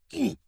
attack_punch.wav